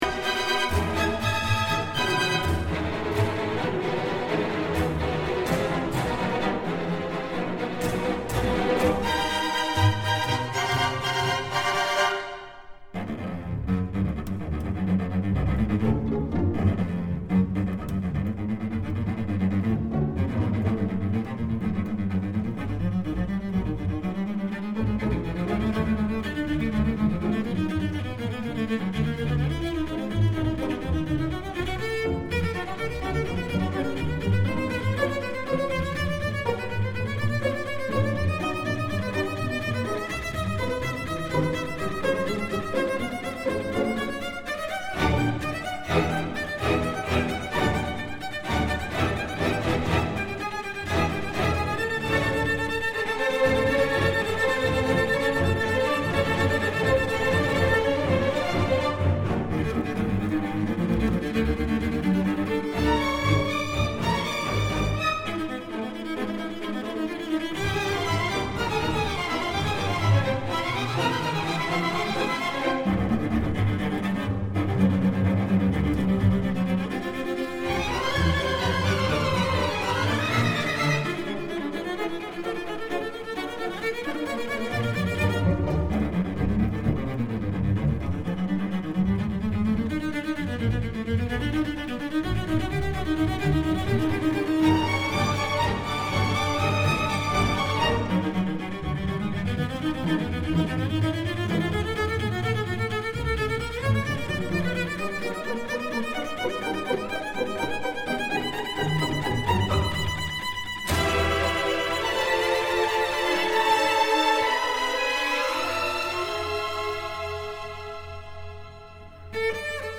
for Solo Violoncello and Strings 為大提琴與弦樂隊而作